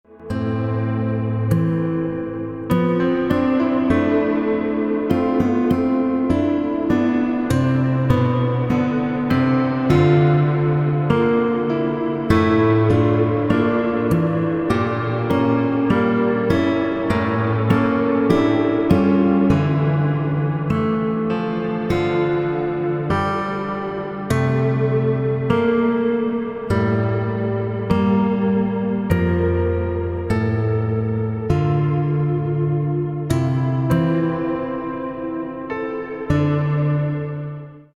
• Качество: 192, Stereo
спокойные
без слов
инструментальные
dark ambient
Neo-Classic